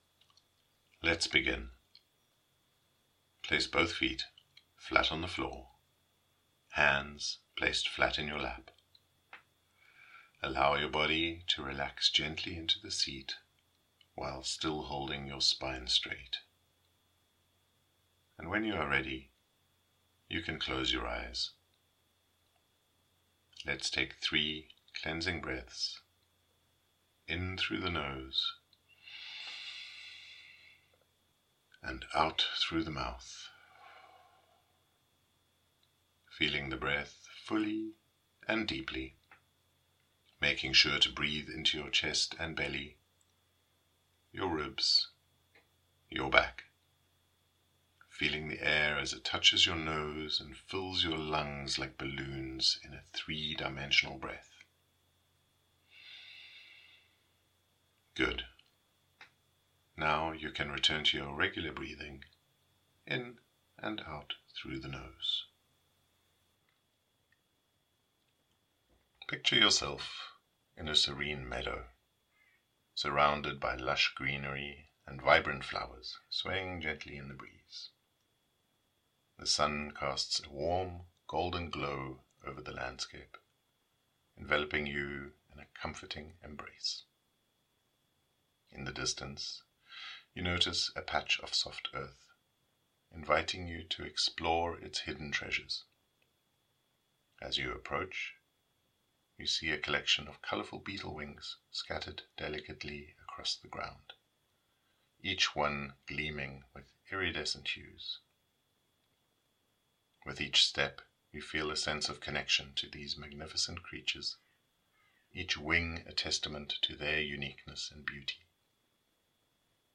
Beetle Mania Meditation
BL04-meditation-beetle-mania.mp3